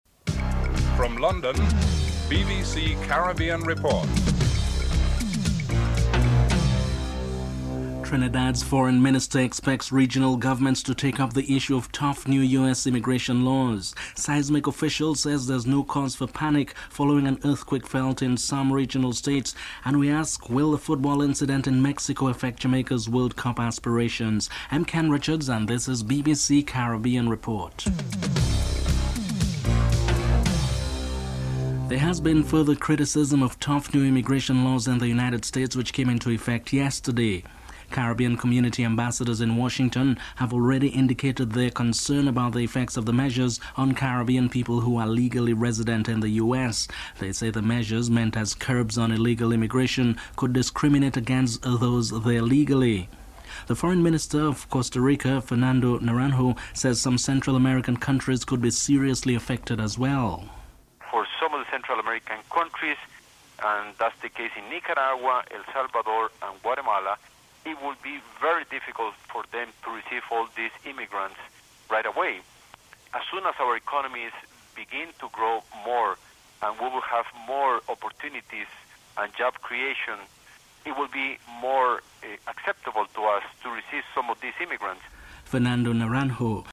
1. Headlines (00:00-00:30)
Fernando Naranjo, Foreign Minister of Costa Rica and Ralph Maraj, Foreign Minister of Trinidad and Tobago are interviewed (00:31-04:02)
Dr. Vaughn Lewis, Prime Minister of St Lucia is interviewed.